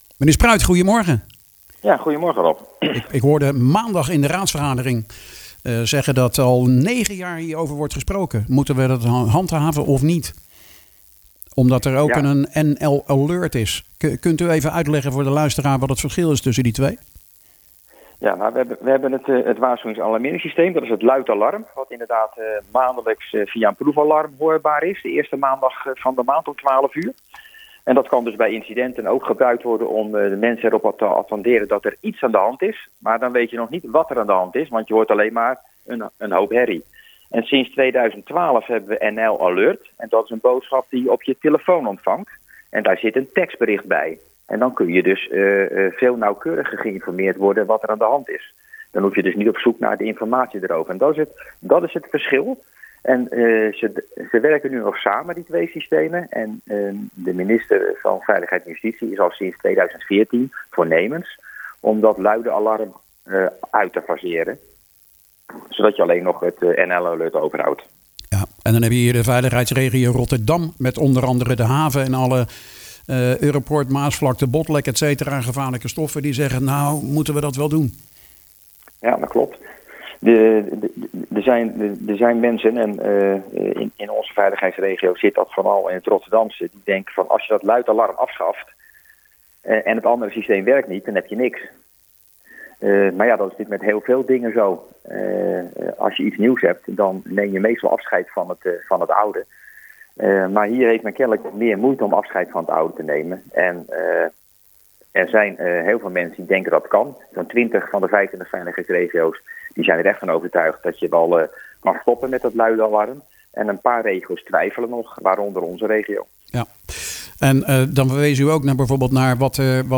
praat erover met Leefbaar Capelle raadslid Gerrit Spruit.